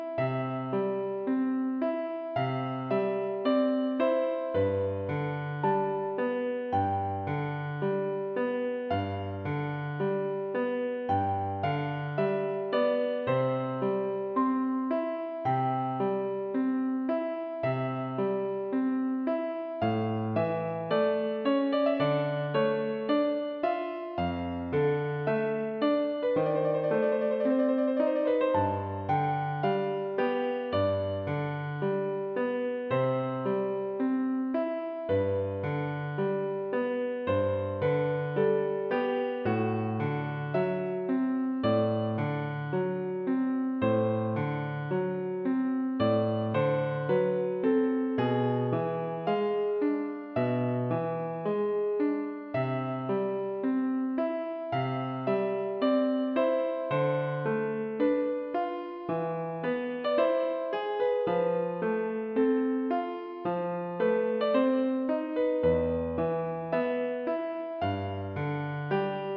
captures the solemn and dramatic character